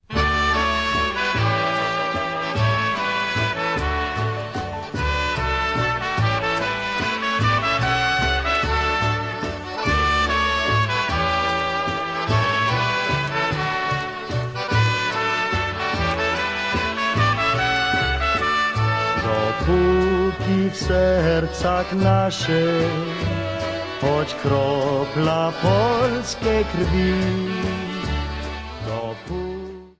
Vocals & Drums
Trumpet
Clarinet & Sax
Accordion
Bass
Piano